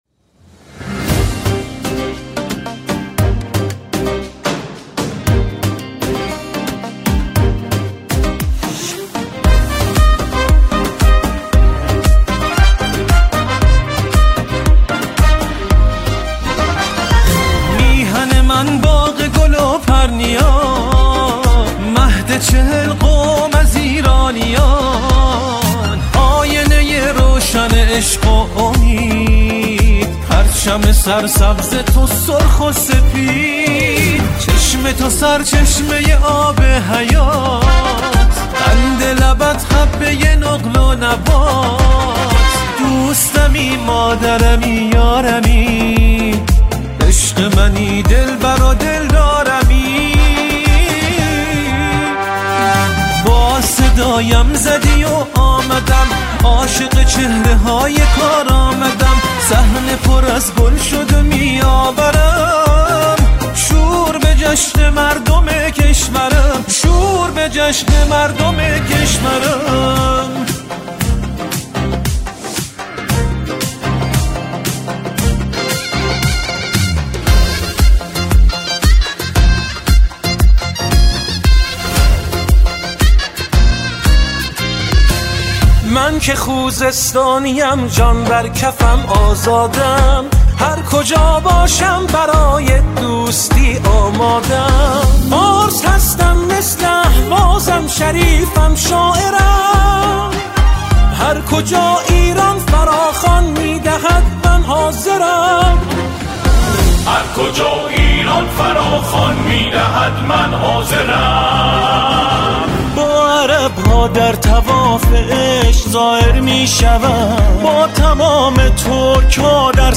آنها در این قطعه، شعری را درباره انتخابات همخوانی می‌کنند.